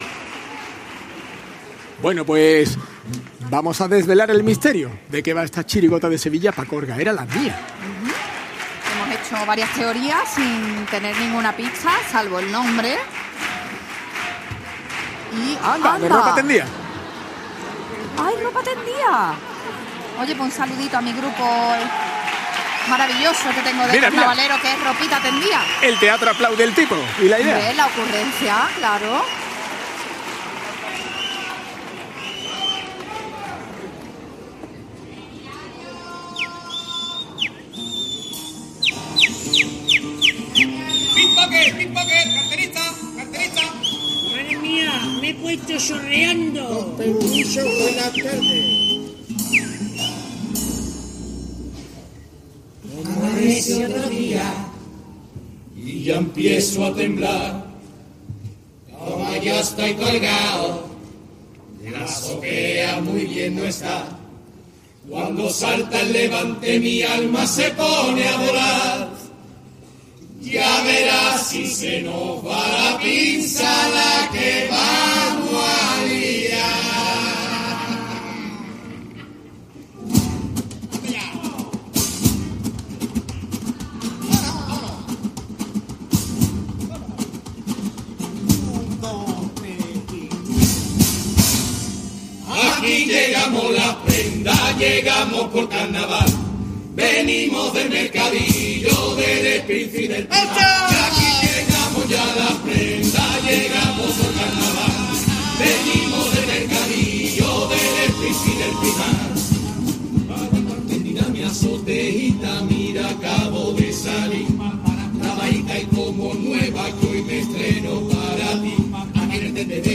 Escucha y descarga el audio de Pá colgaera la mía de Preliminares del Concurso Oficial de Agrupaciones del Carnaval de Cádiz (COAC) 2025 en formato MP3 y de manera gratuita
Escucha la actuación de la Chirigota Pá colgaera la mía en la fase preliminares del COAC Carnaval de Cádiz 2025.